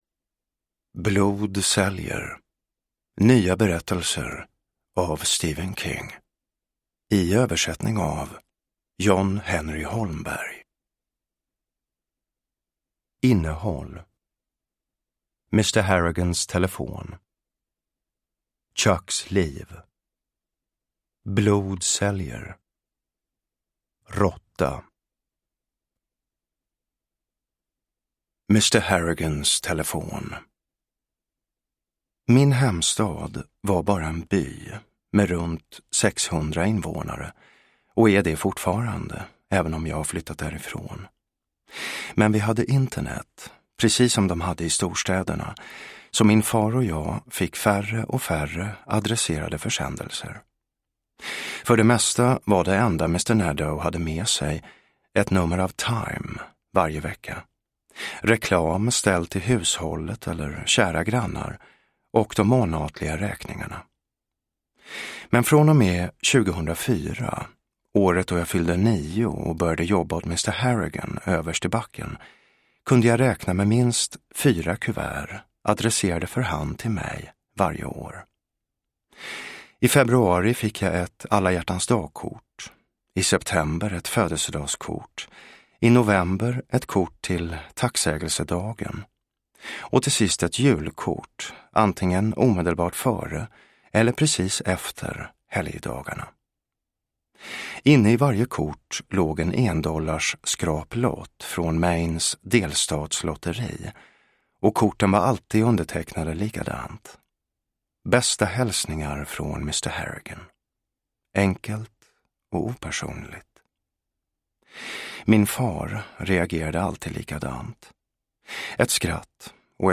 Blod säljer : nya berättelser – Ljudbok – Laddas ner
Uppläsare: Jonas Malmsjö, Jessica Liedberg